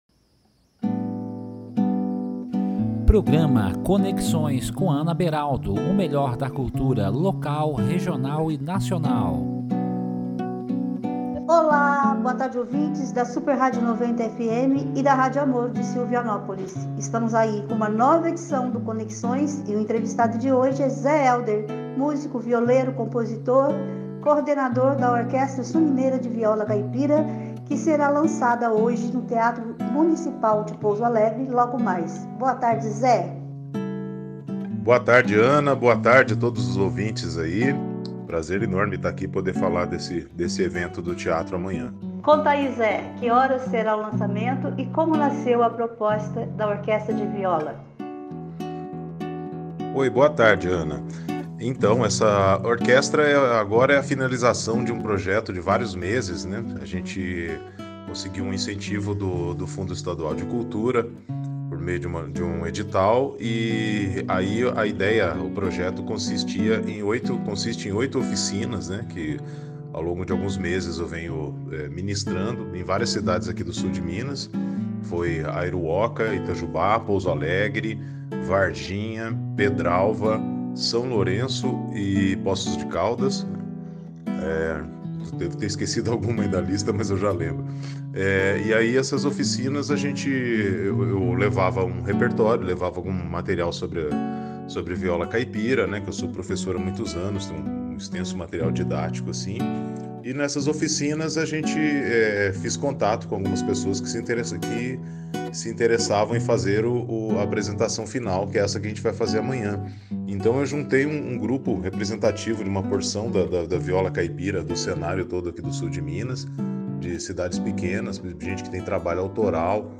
Com ênfase para entrevistas na área cultural, o Programa vai ao ar aos domingos, às 13h30.